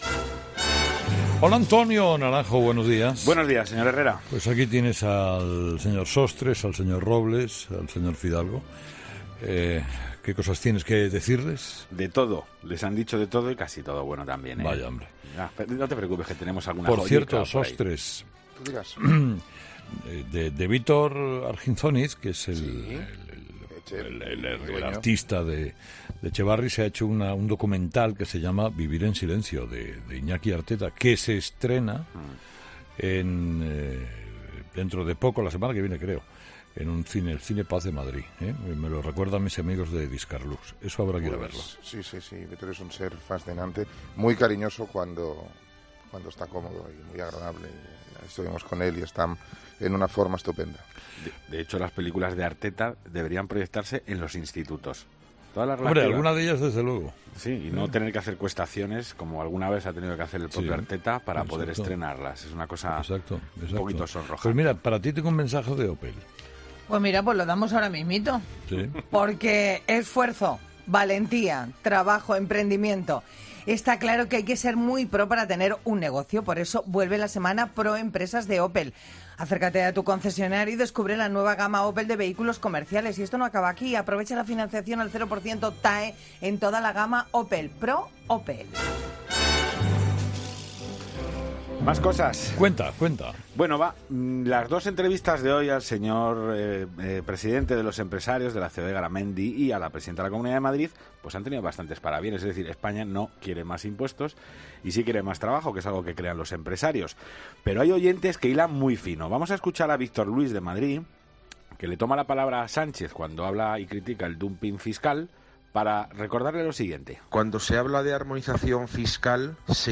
Avalancha de mensajes en el contestador de ‘Herrera en COPE’.